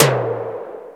Index of /90_sSampleCDs/Roland - Rhythm Section/TOM_E.Toms 1/TOM_Analog Toms1
TOM MIAMIT0S.wav